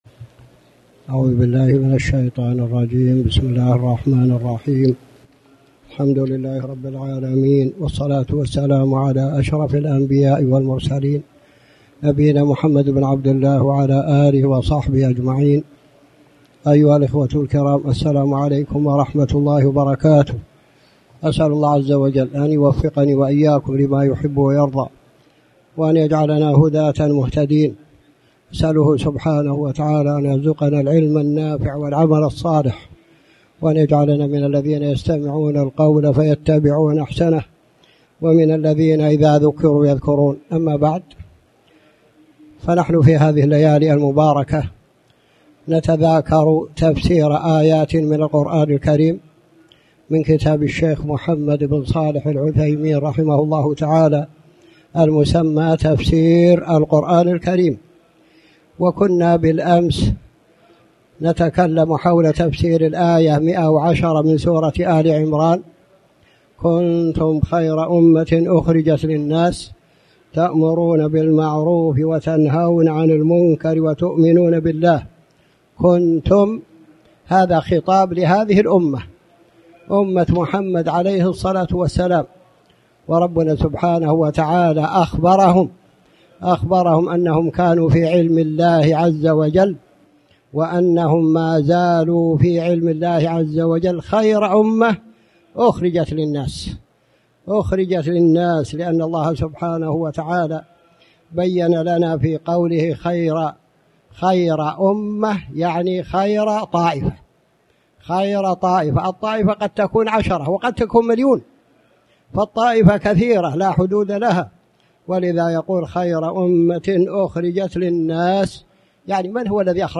تاريخ النشر ٢٥ ربيع الأول ١٤٣٩ هـ المكان: المسجد الحرام الشيخ